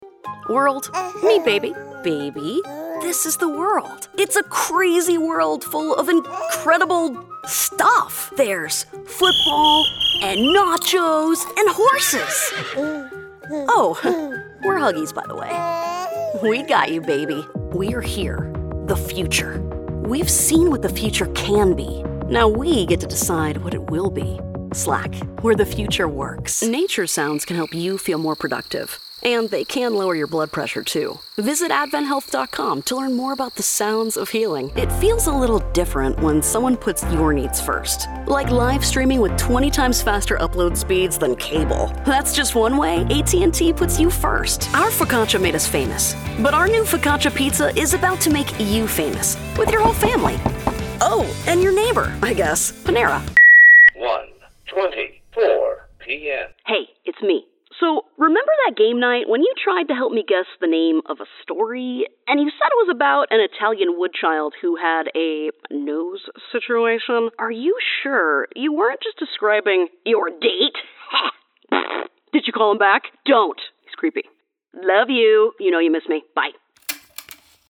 Englisch (Amerikanisch)
Kommerziell, Junge, Natürlich, Freundlich, Corporate
Kommerziell